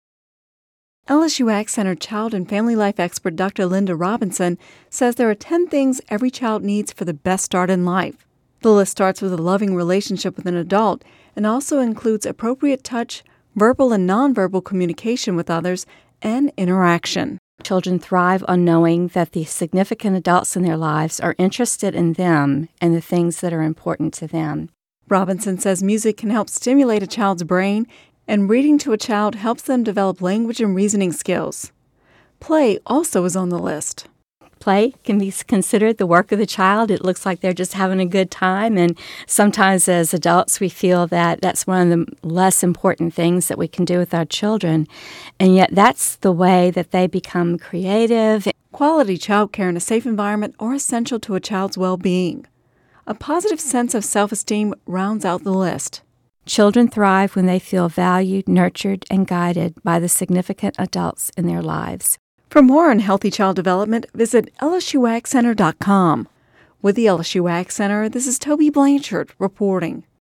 Radio News 08/09/10